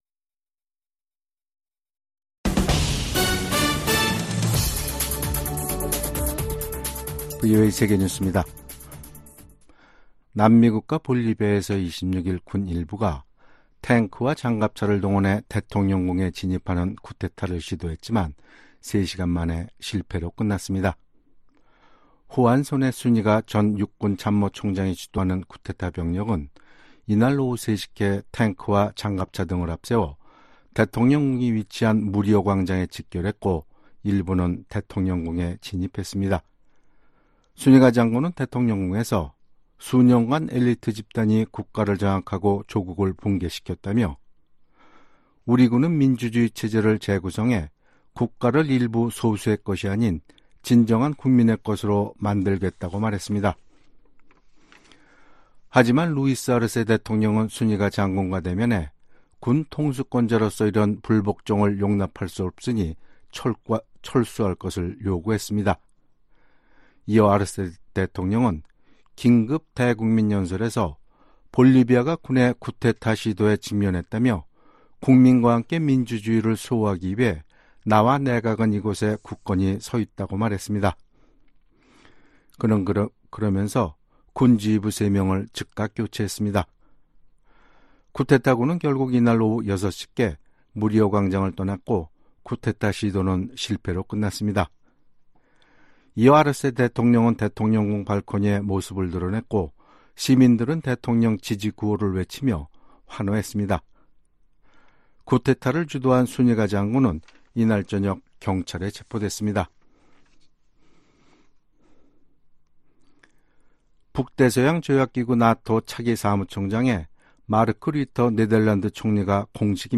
VOA 한국어 간판 뉴스 프로그램 '뉴스 투데이', 2024년 6월 27일 3부 방송입니다. 미국 정부는 러시아의 우크라이나 점령지로 북한 노동자가 파견될 가능성에 반대 입장을 분명히 했습니다.